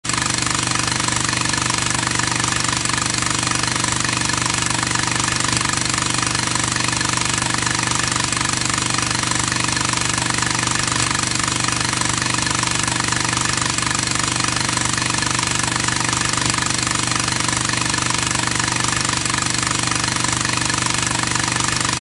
فهذه أنشوده جديدة تتلألأ في سماء هذا المنتدى الكبير الرائع
والتي تمت تسجيلها بعدة أستديوهات وعدة مهندسين
ملاحظة/ الأنشوده مليئة بالألغام النشازية فاحذروا